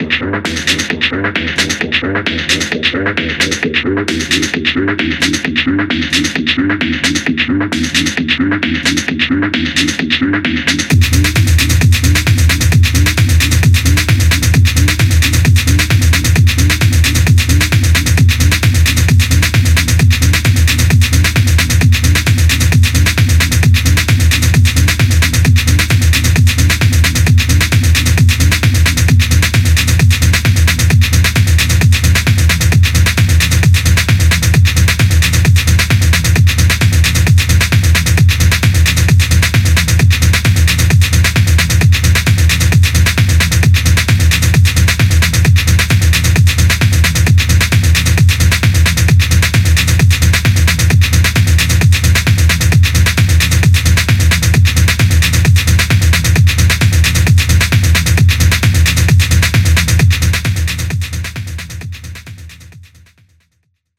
よりハード・テクノに接近したインダストリアルなルーピーグルーヴが強烈な